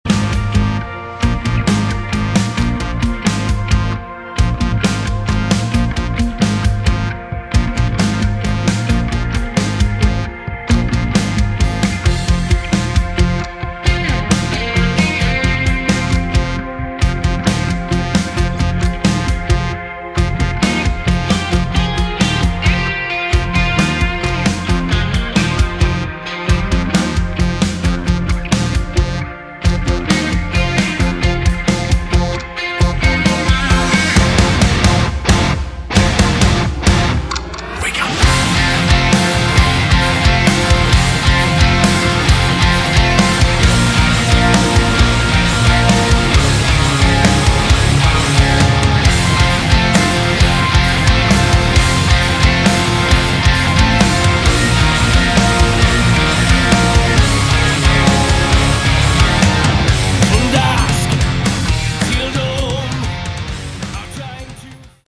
Category: Rock
keyboards, backing vocals